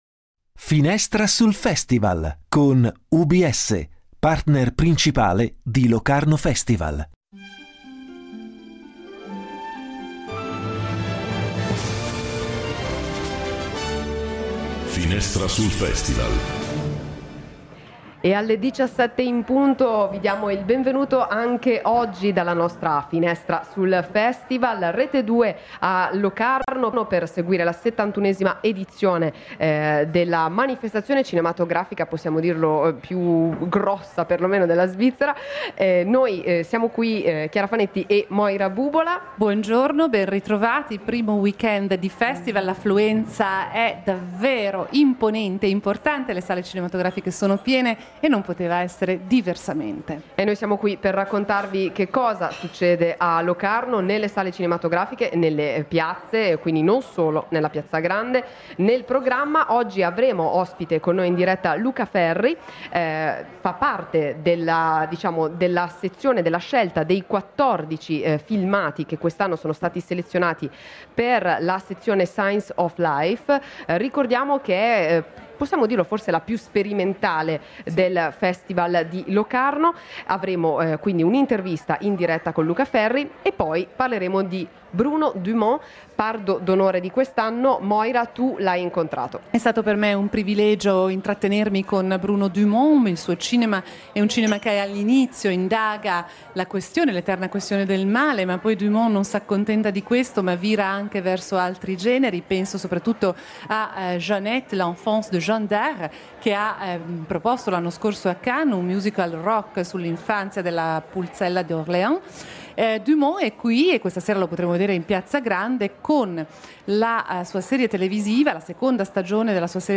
In diretta con noi
Intervista a Bruno Dumont, regista francese, a Locarno per ricevere il Pardo d’onore.